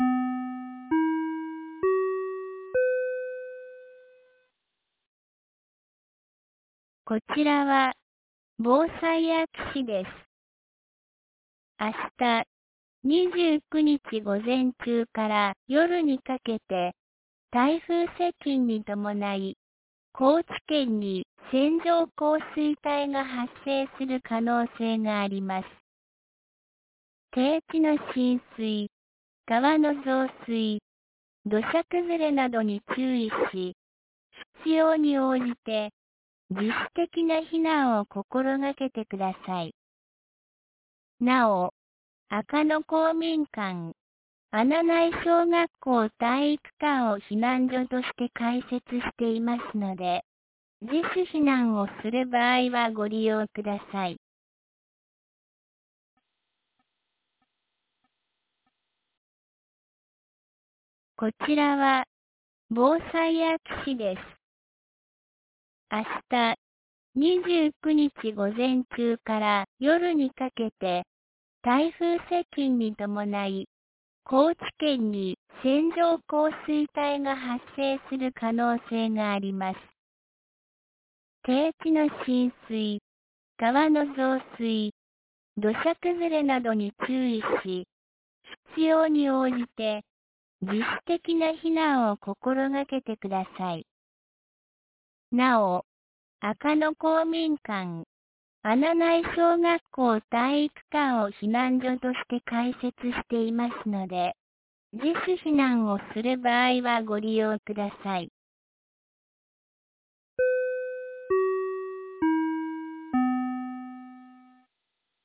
2024年08月28日 15時56分に、安芸市より穴内、赤野へ放送がありました。